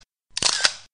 Ambient sound effects
Descargar EFECTO DE SONIDO DE AMBIENTE FOTO VA FOTO - Tono móvil